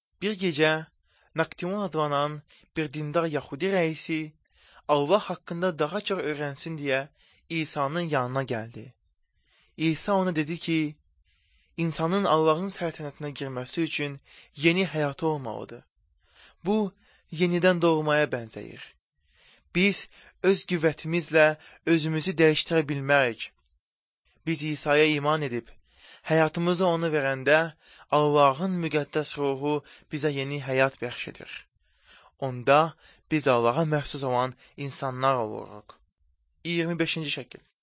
The very Persian-sounding intonation and rhythm of the voice, even the overall quality of the vowels, makes me suspect this is a bilingual Iranian speaker, which is another clue that this might be Azeri, but from the Iranian side of the border, and not Azerbaijan itself.